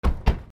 110 車 ドアを閉める
/ E｜乗り物 / E-10 ｜自動車